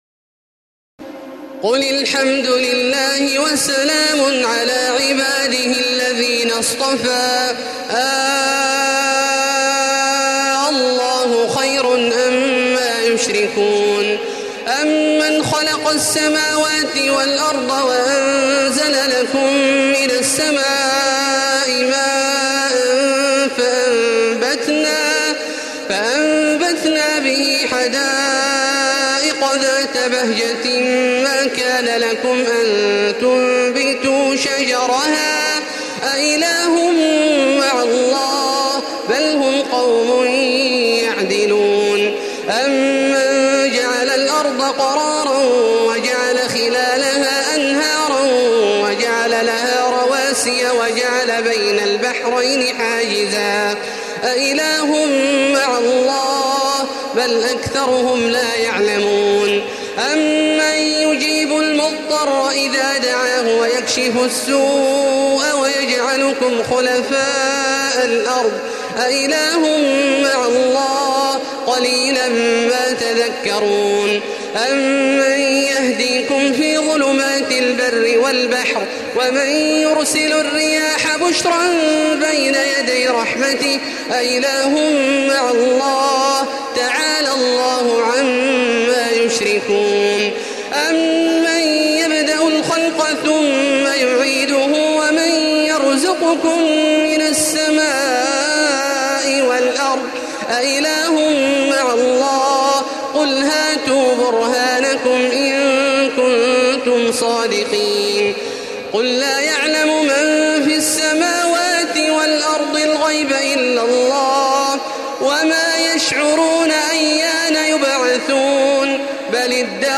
تراويح الليلة العشرون رمضان 1435هـ من سورتي النمل(59-93) و القصص(1-50) Taraweeh 20 st night Ramadan 1435H from Surah An-Naml and Al-Qasas > تراويح الحرم المكي عام 1435 🕋 > التراويح - تلاوات الحرمين